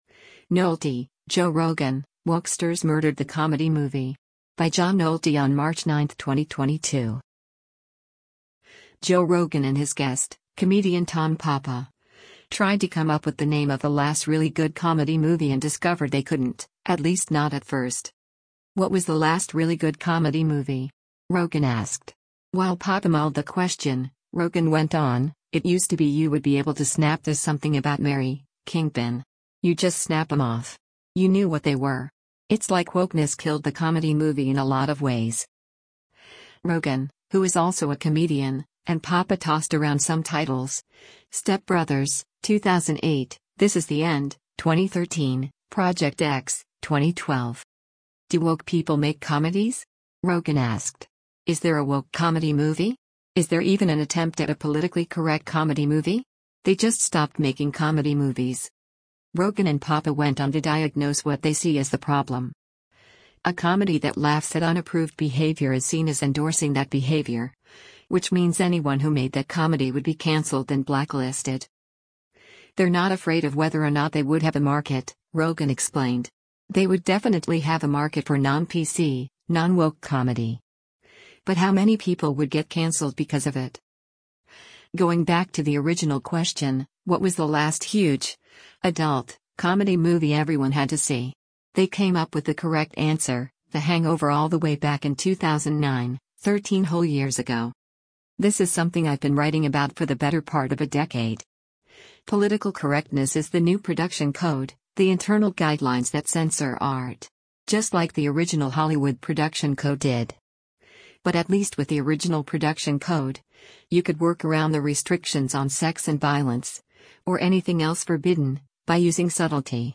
Joe Rogan and his guest, comedian Tom Papa, tried to come up with the name of the “last really good comedy movie” and discovered they couldn’t — at least not at first.